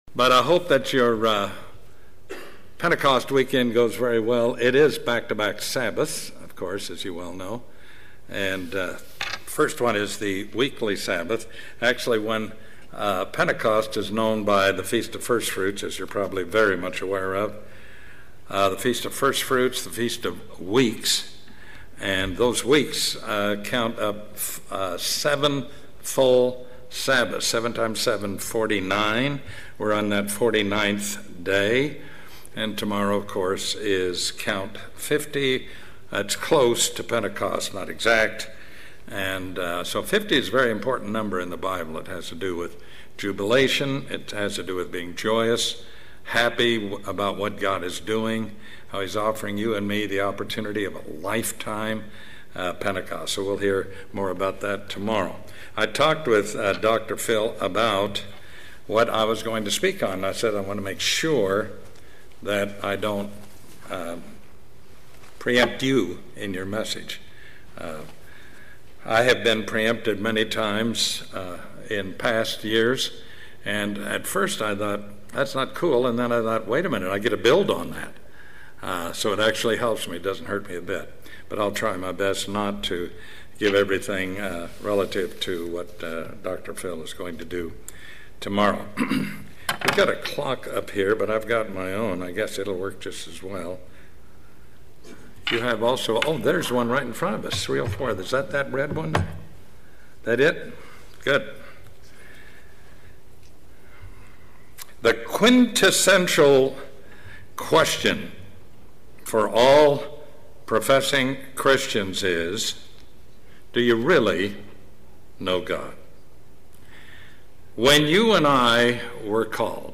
Given in Atlanta, GA